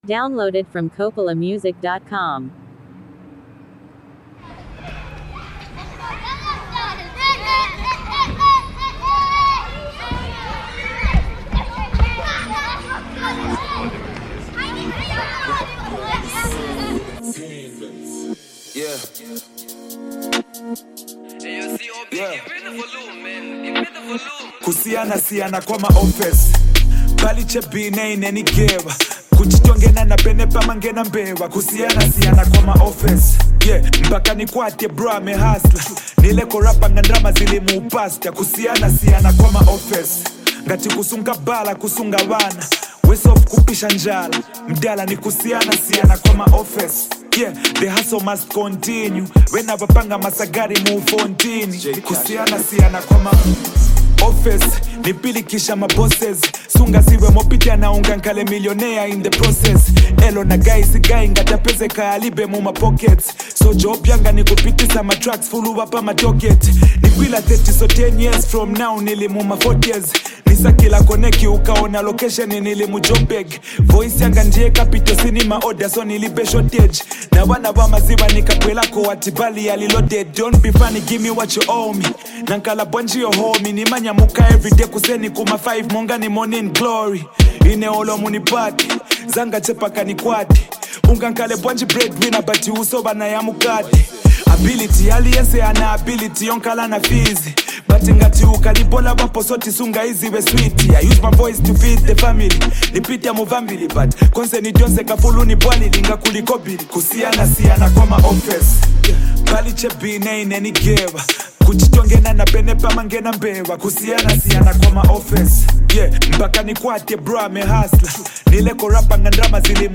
a confident and uplifting performance
an inspirational anthem